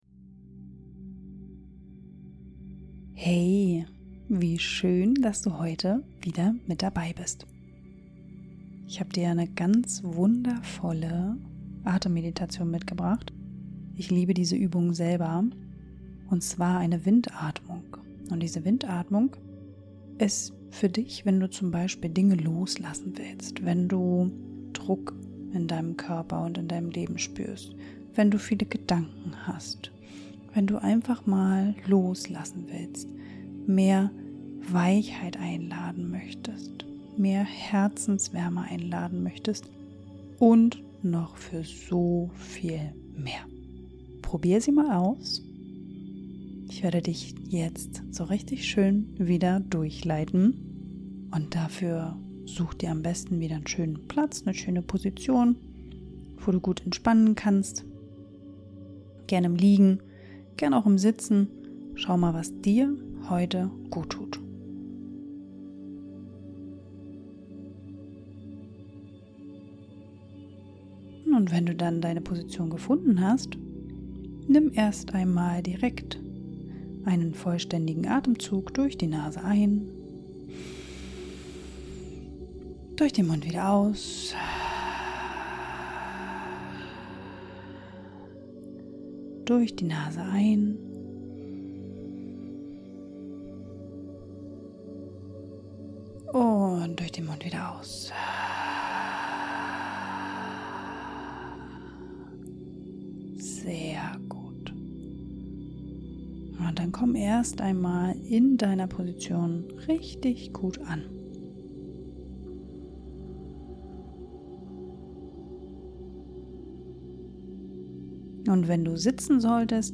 Diese Meditation ist für dich, wenn du spürst, dass dein Körper nach Ruhe ruft, aber dein Kopf einfach nicht still sein will.